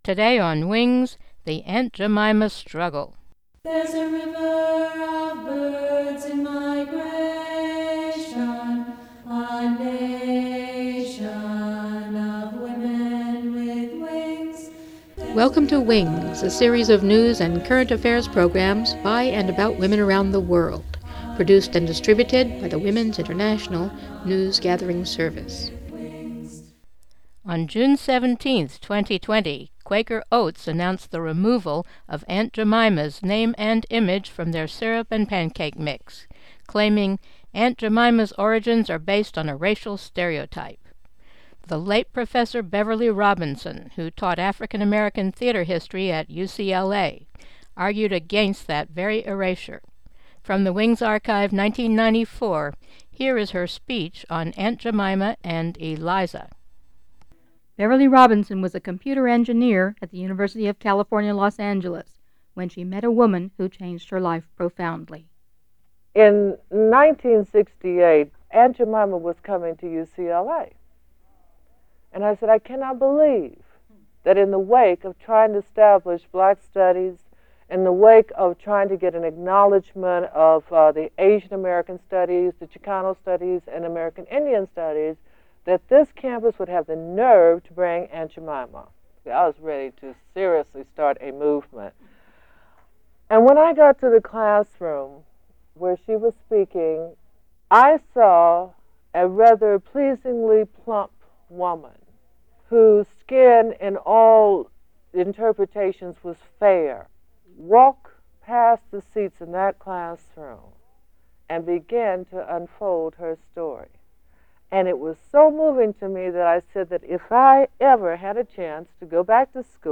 Mono
NOTE: n-words bleeped for this new edition Listen All